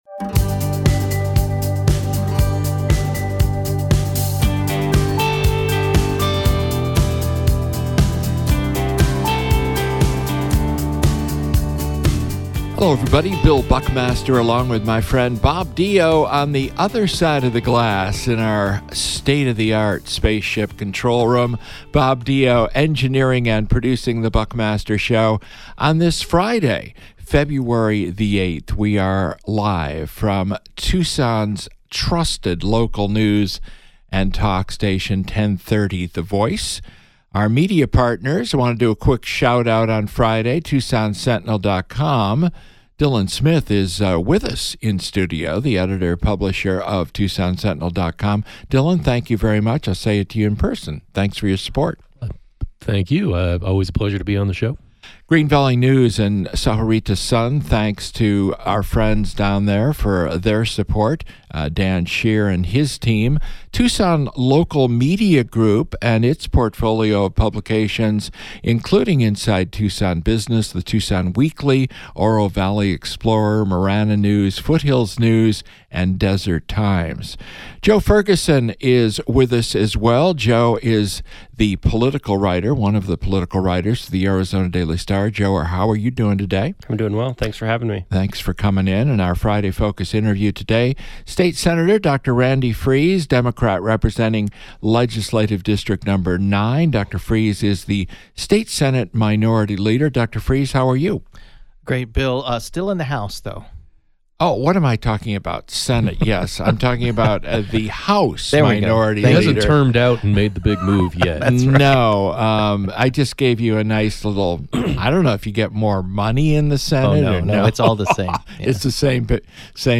The Friday Focus interview is with State House Assistant Minority Leader Dr. Randy Friese (D-District 9).